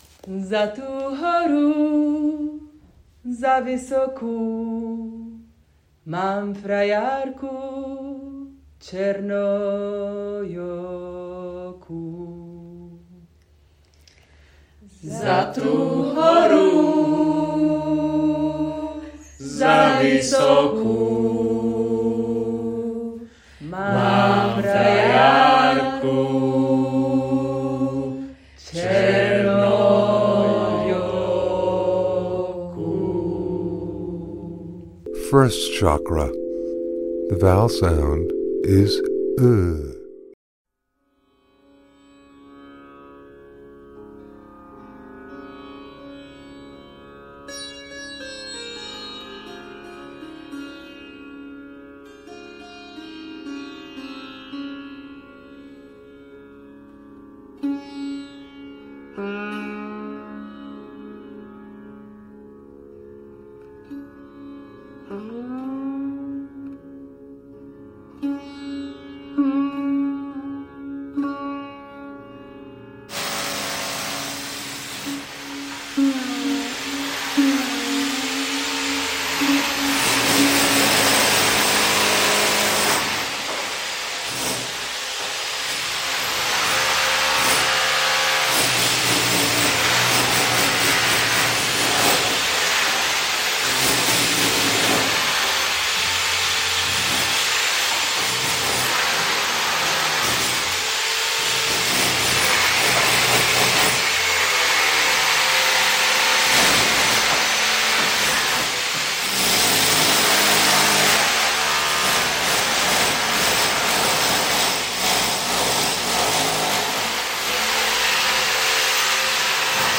en Our goal was to use the sounds we worked with during this week (tier 8) and create a single composition that would work independently.
During the week, we were generally interested in acoustic resonance, specifically vibrations through voice and body, bone resonance, folk songs, working environment sounds, and personal recordings from mobile phones.
en collage
en 8. Sound/music